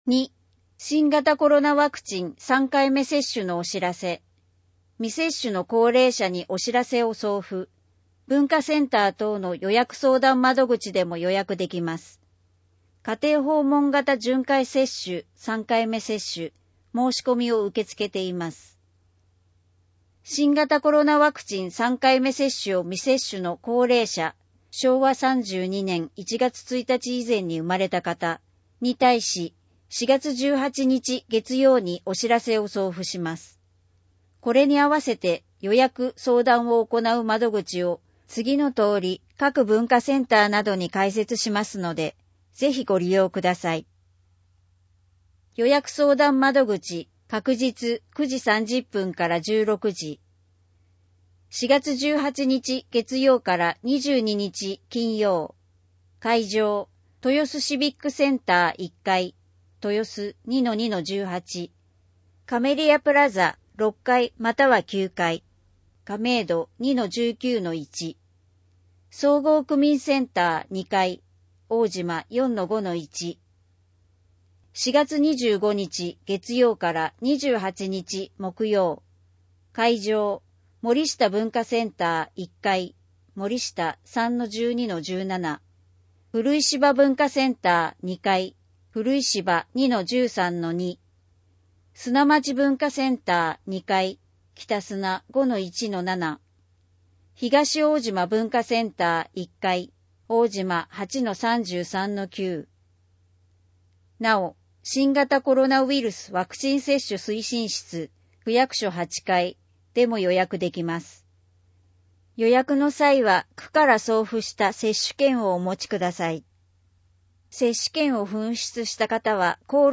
声の広報